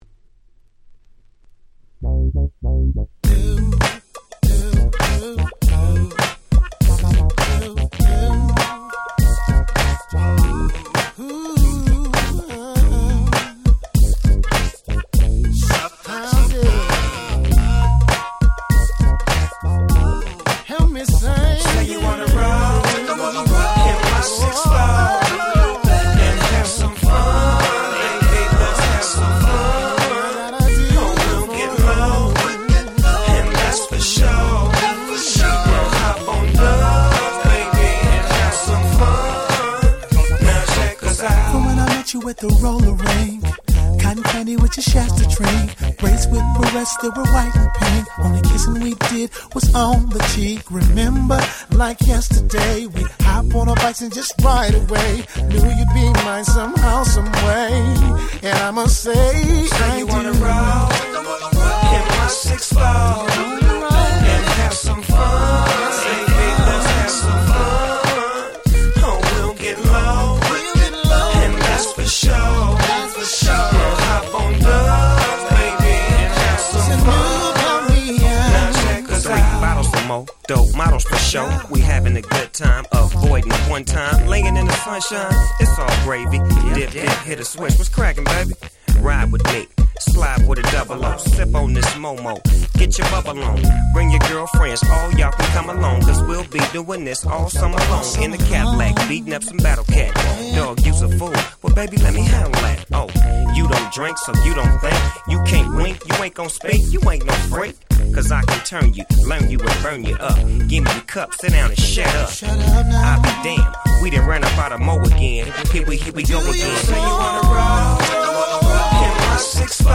01' Super Nice West Coast Hip Hop !!
正統派ウエッサイFunkに仕上がっております！！